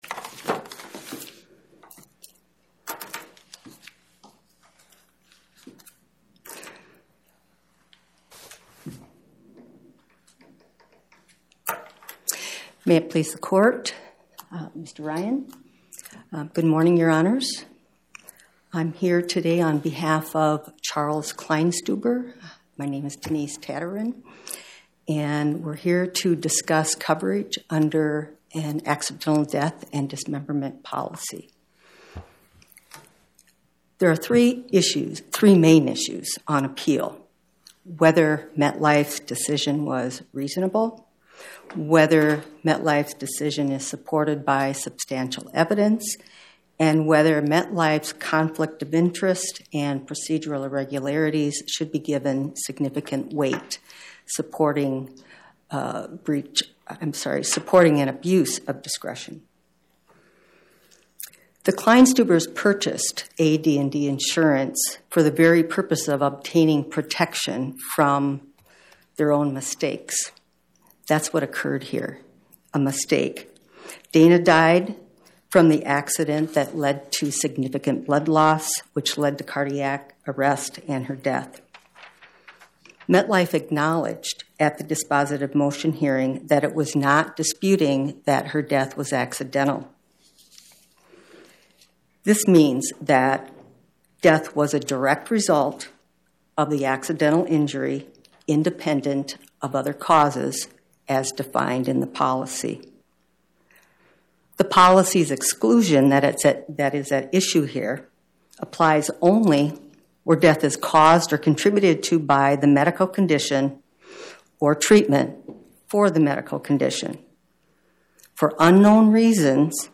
Co. Podcast: Oral Arguments from the Eighth Circuit U.S. Court of Appeals Published On: Tue Mar 17 2026 Description: Oral argument argued before the Eighth Circuit U.S. Court of Appeals on or about 03/17/2026